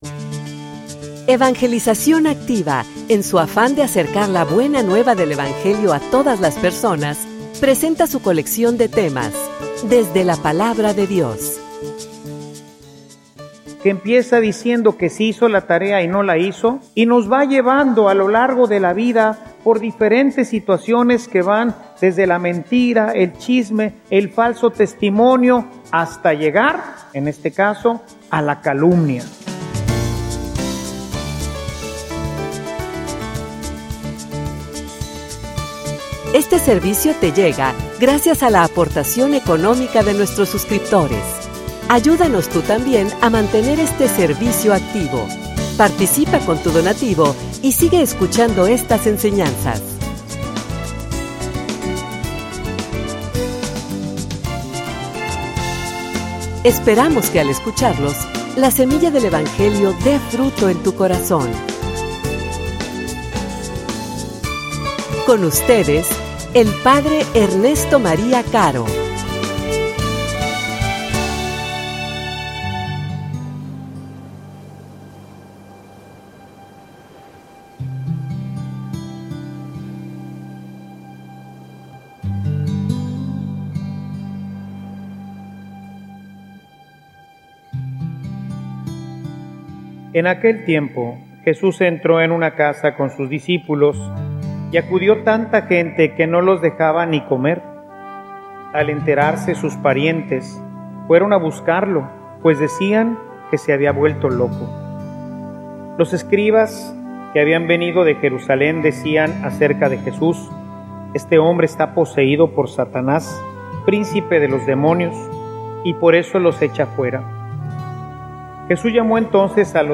homilia_Las_mentiras_camino_de_destruccion.mp3